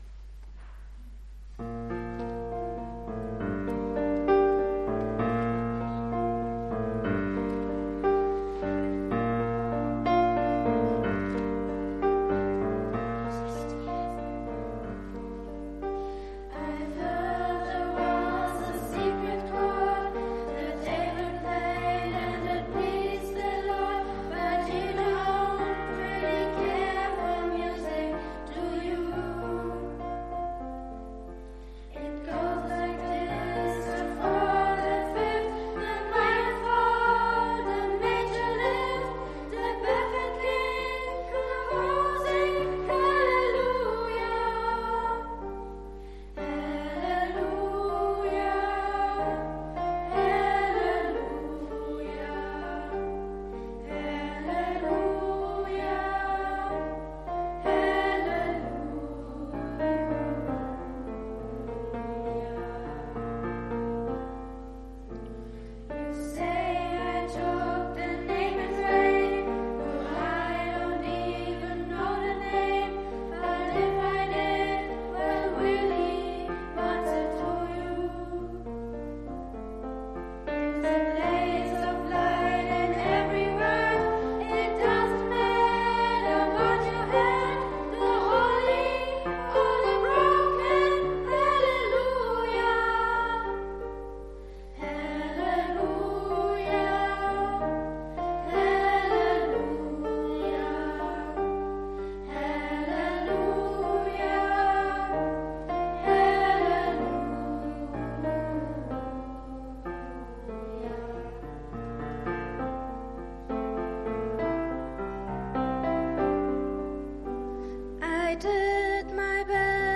Gottesdienst mit Erklärungen